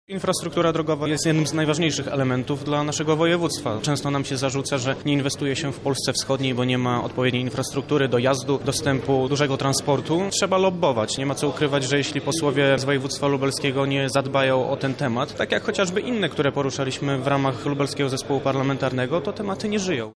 – Musimy zadbać o inwestycje w naszym regionie – mówi poseł Twojego Ruchu, Marek Poznański: